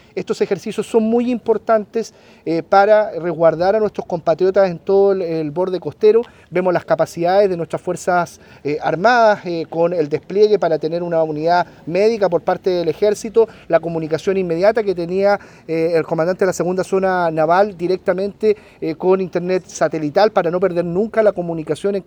El delegado presidencial, Eduardo Pacheco, agregó que esta instancia sirvió para probar los equipos que tiene el Gobierno, no solo para este tipo de emergencias, sino para cualquier eventualidad.